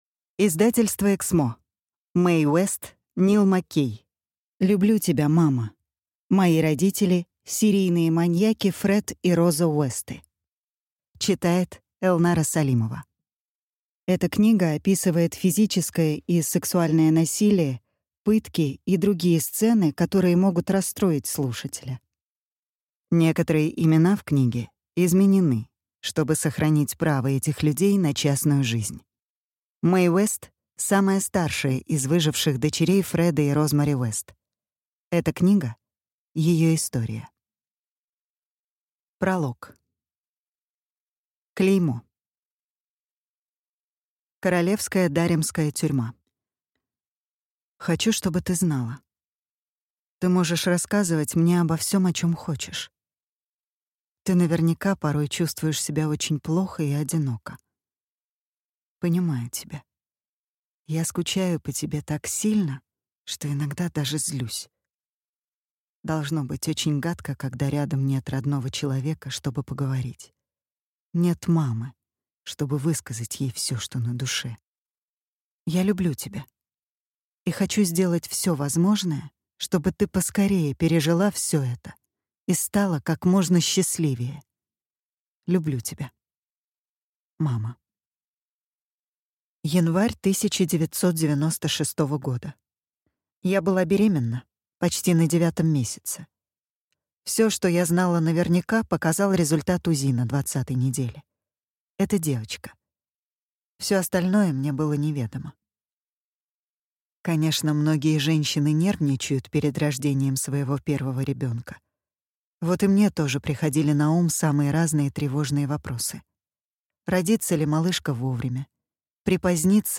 Аудиокнига Люблю тебя, мама. Мои родители – маньяки Фред и Розмари Уэст | Библиотека аудиокниг